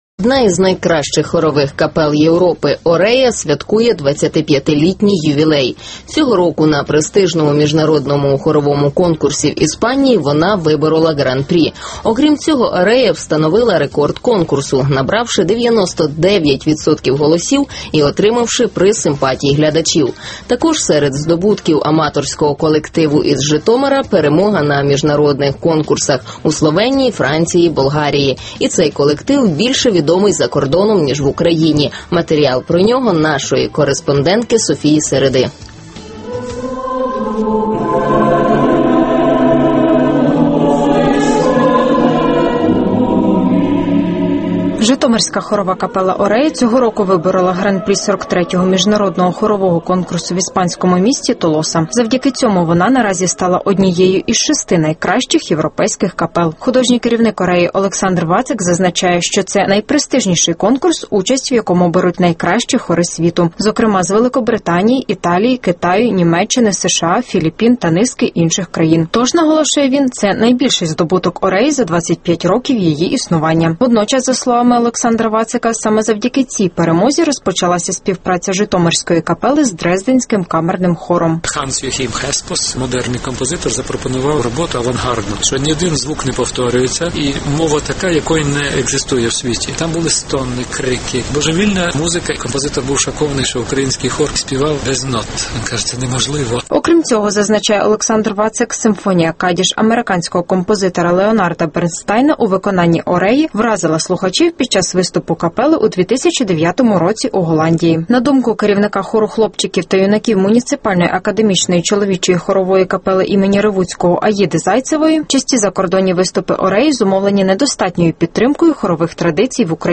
«Орея» – українська хорова капела, що співає без нот